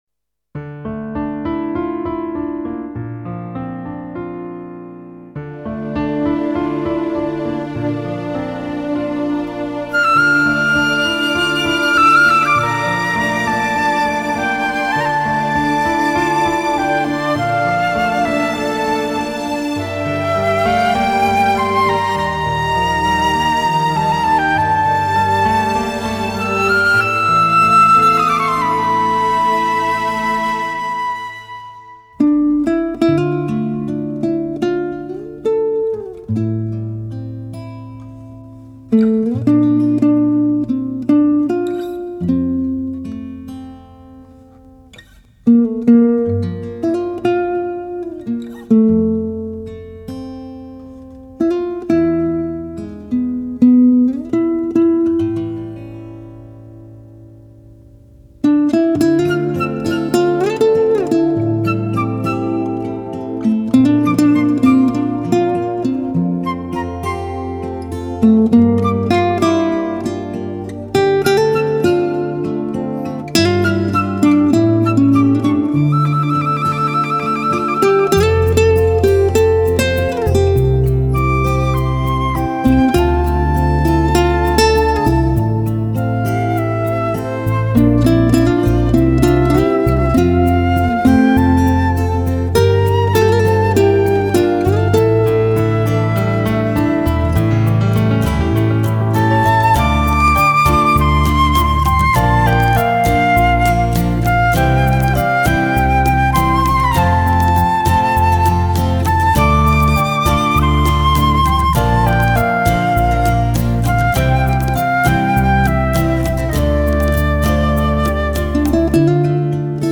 Nhạc Thư Giãn